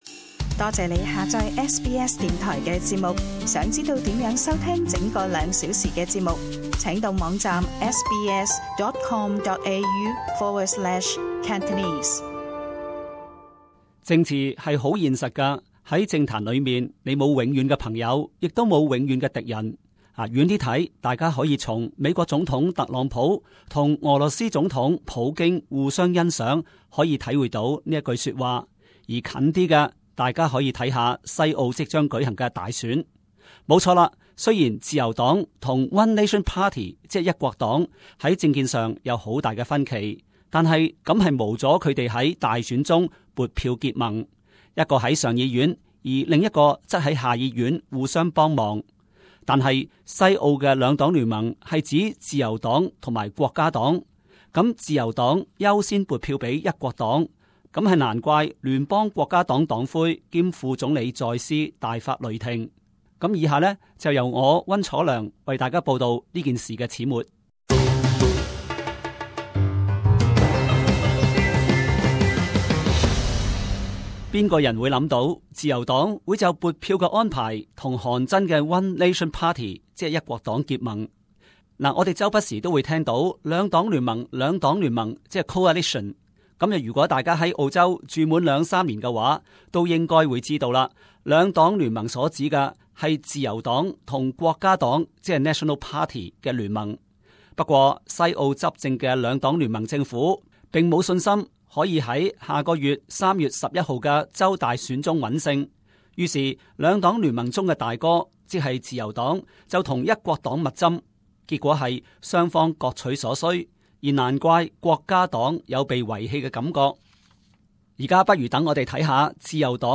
【時事報導】 西澳大選: 自由黨拉攏一國黨